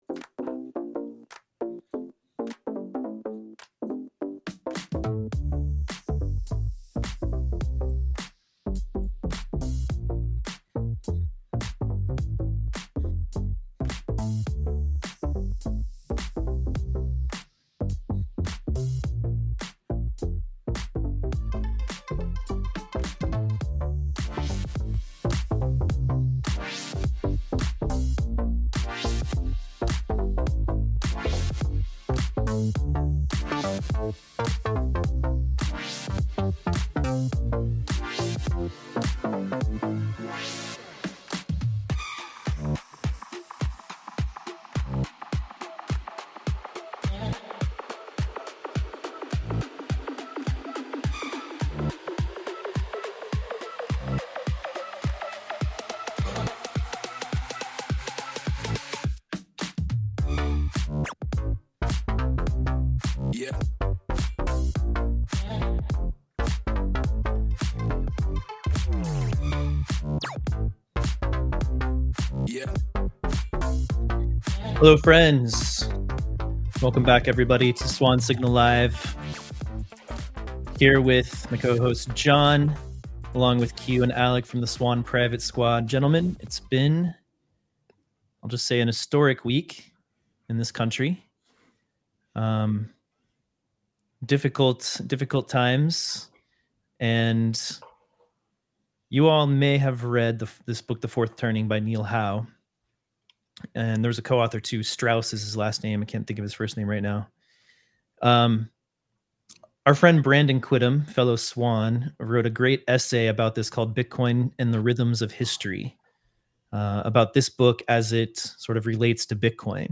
Swan Signal Live brings you interviews, news, analysis, and commentary on Bitcoin, macro, finance, and how Bitcoin is the foundation for a brighter future for us all.